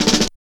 51 SN BUZZ-R.wav